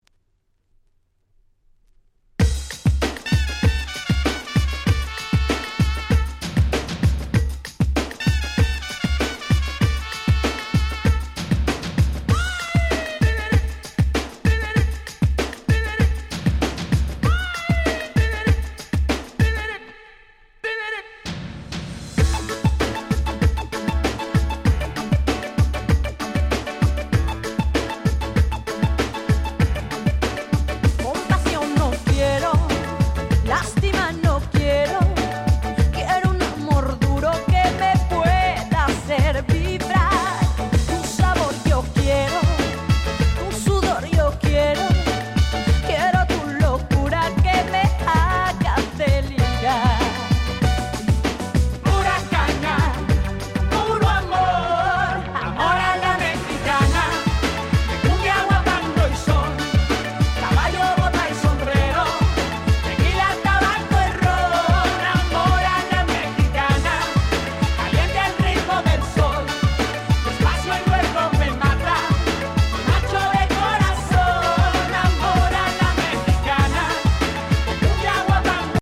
97' Smash Hit Latin !!
France Press Only Remix !!
大変陽気な感じで元気出ます！